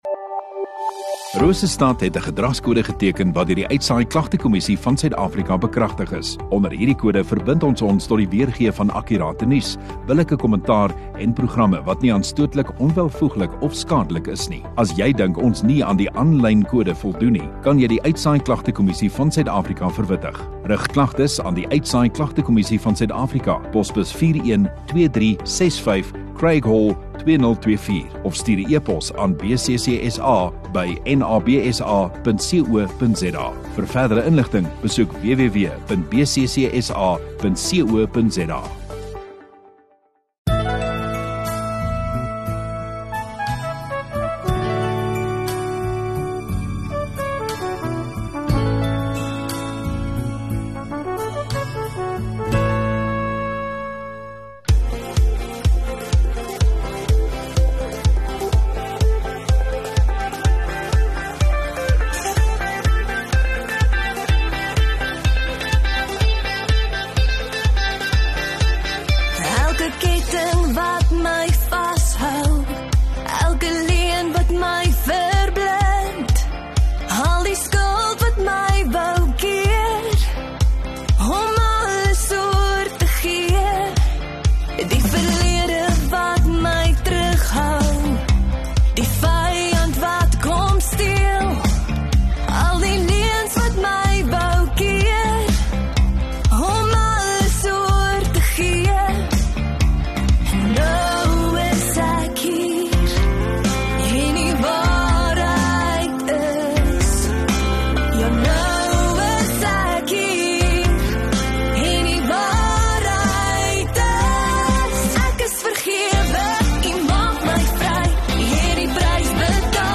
9 Mar Sondagoggend Erediens